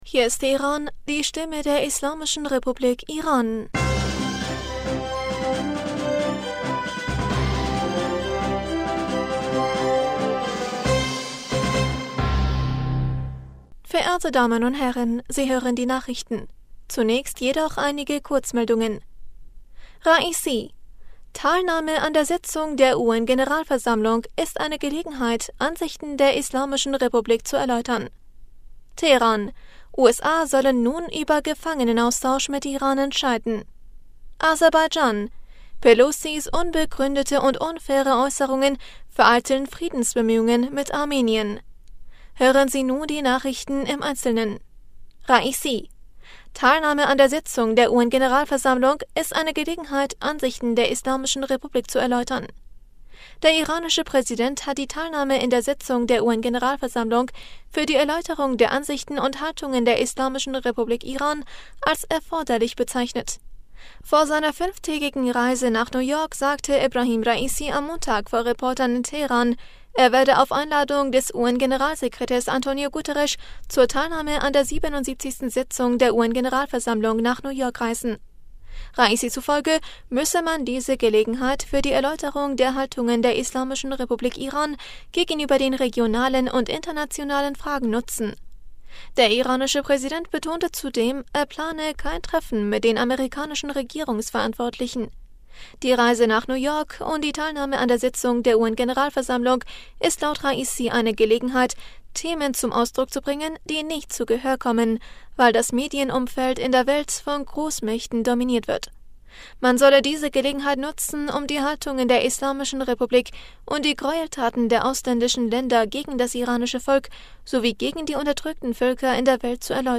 Nachrichten vom 19. September 2022
Die Nachrichten von Montag, dem 19. September 2022